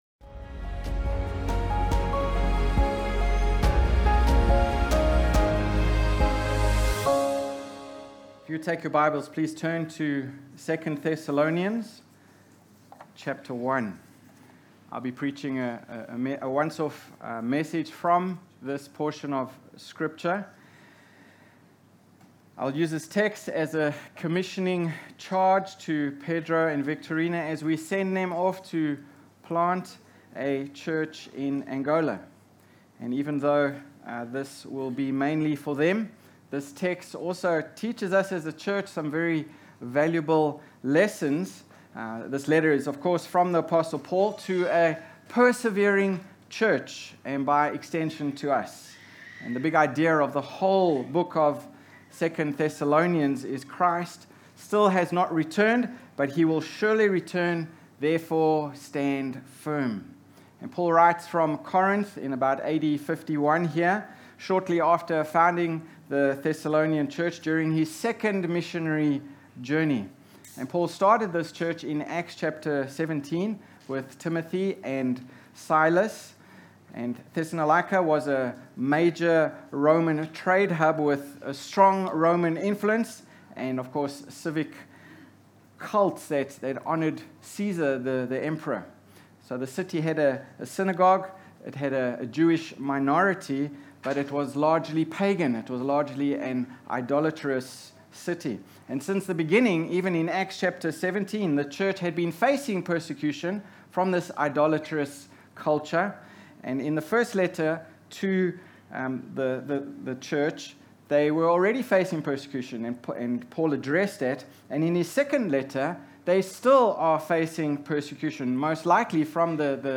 Sermons Podcast - Worthy of His Calling | Free Listening on Podbean App